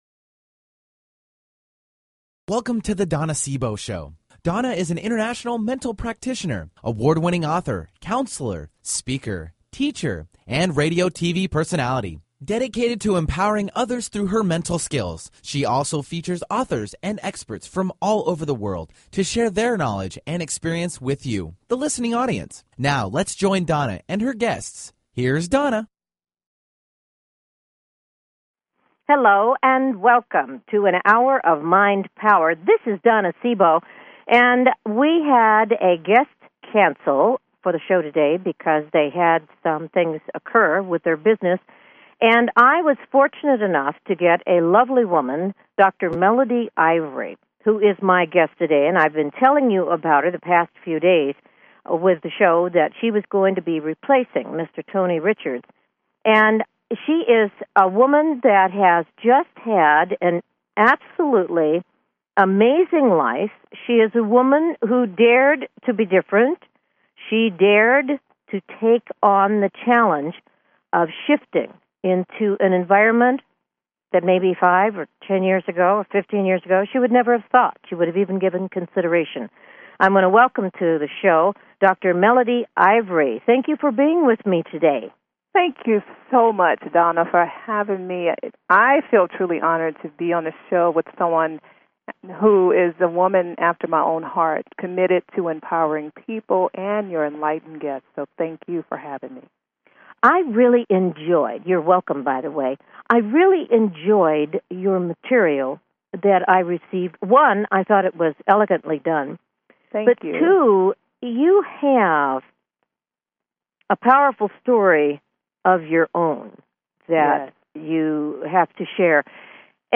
Talk Show Episode, Audio Podcast
Guests on her programs include CEO's of Fortune 500 companies to working mothers.
Tune in for an "Hour of Mind Power". Callers are welcome to call in for a live on air psychic reading during the second half hour of each show.